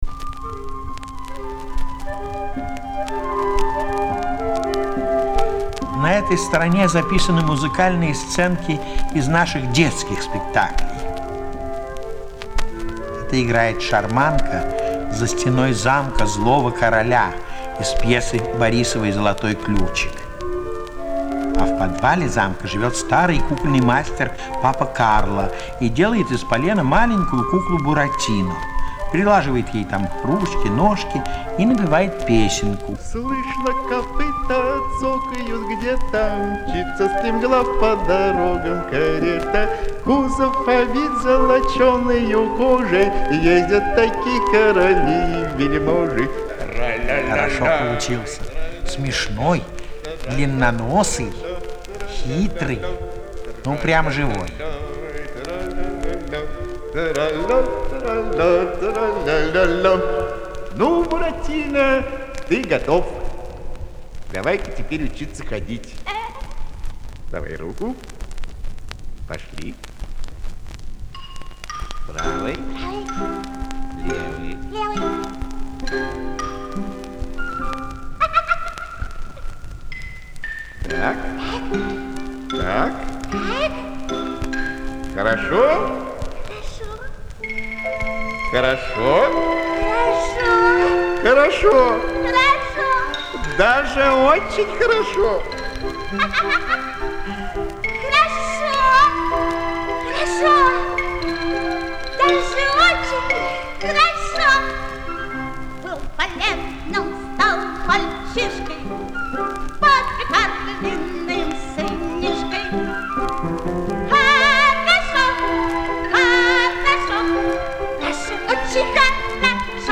Звуковая страница 12 - рассказ С.В.Образцова о кукольном театре.